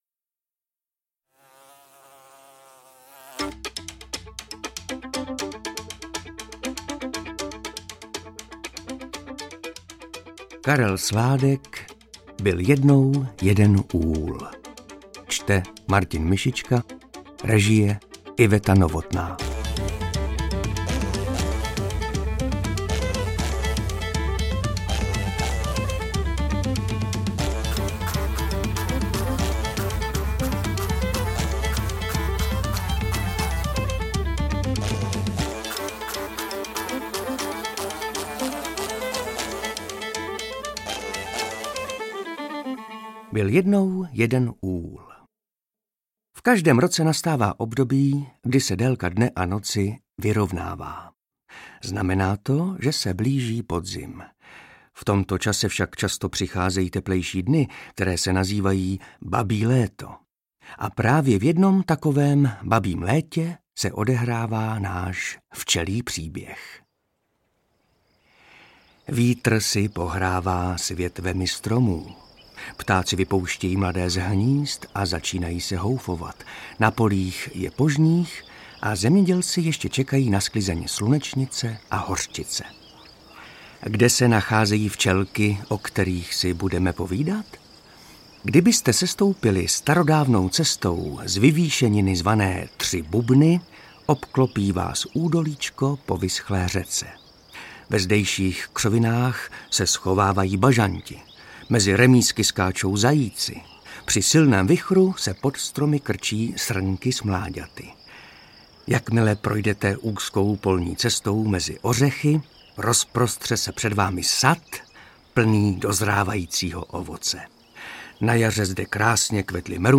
Interpret:  Martin Myšička
AudioKniha ke stažení, 12 x mp3, délka 1 hod. 40 min., velikost 91,5 MB, česky